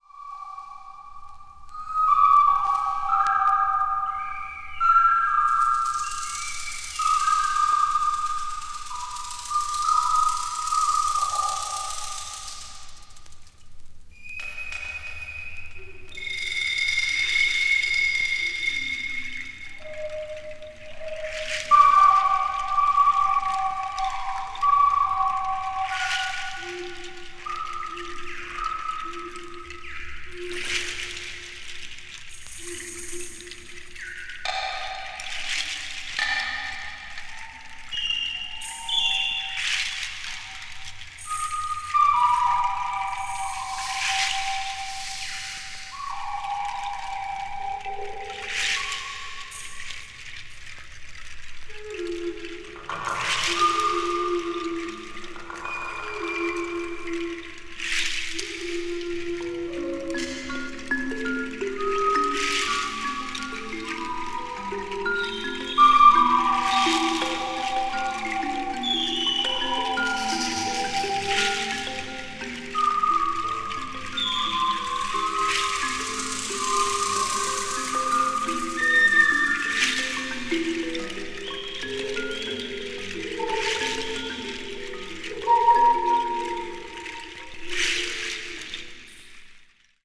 A面にセンターホールが少しずれているため、音にわずかな揺れがありますので、あらかじめご了承のうえお買い求めください。